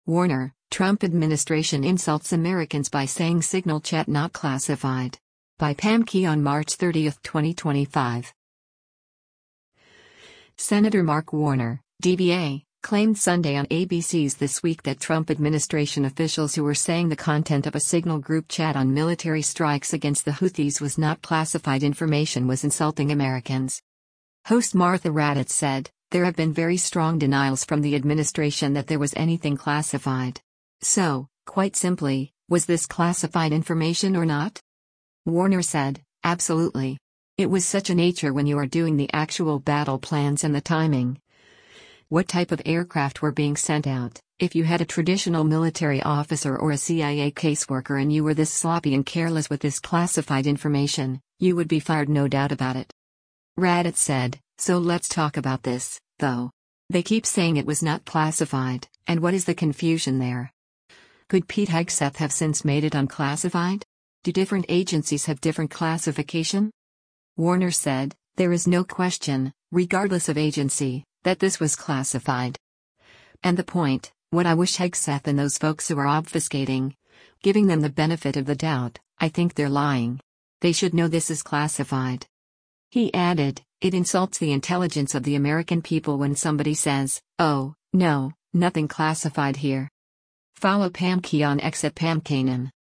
Senator Mark Warner (D-VA) claimed Sunday on ABC’s “This Week” that Trump administration officials who were saying the content of a Signal group chat on military strikes against the Houthis was not classified information was insulting Americans.